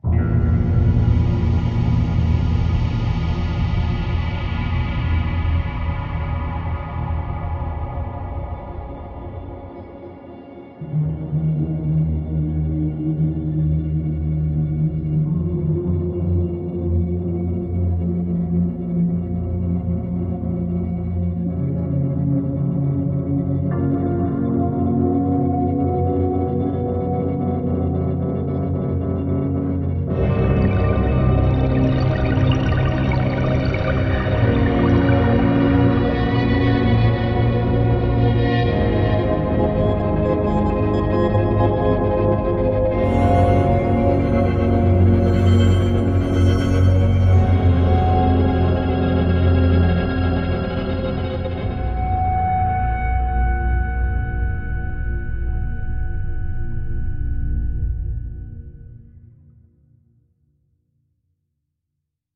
01-Spitfire-Audio-—-Ambient-Guitars.mp3